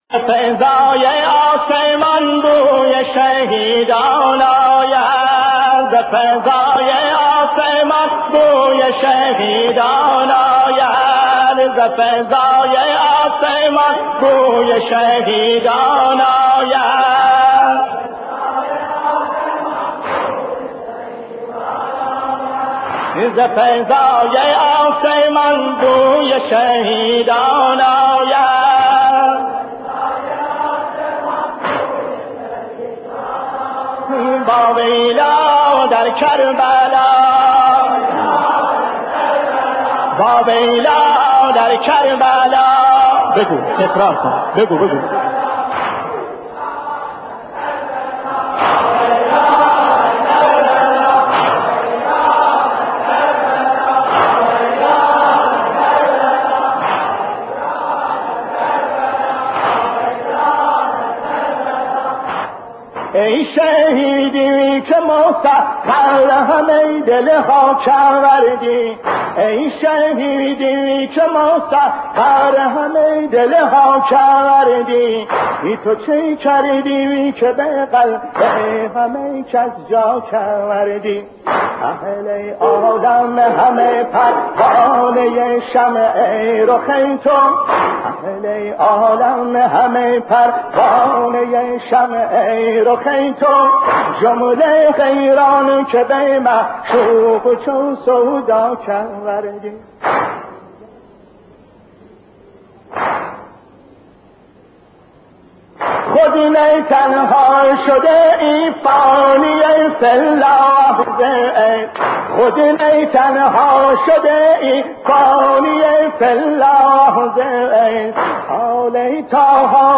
نوحه خون